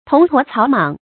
铜驼草莽 tóng tuó cǎo mǎng
铜驼草莽发音